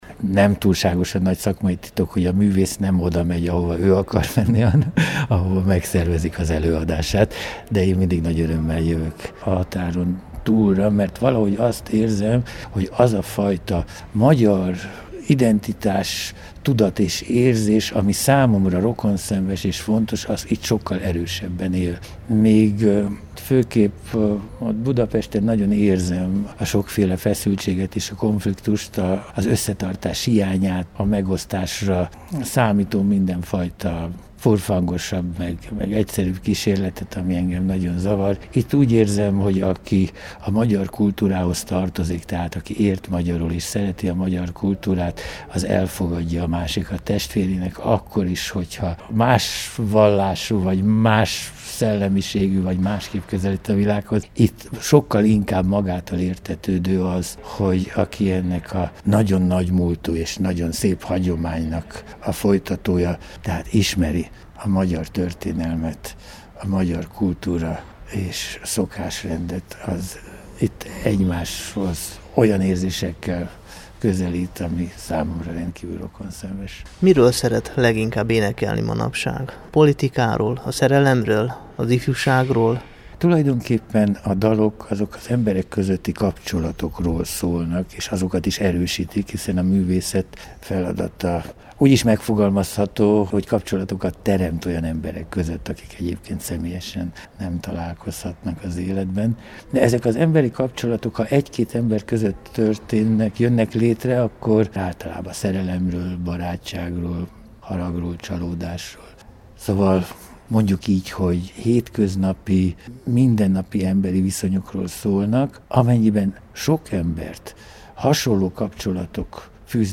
Hallgassa meg a Temesvári Rádió ifjúsági műsora számára készült interjút!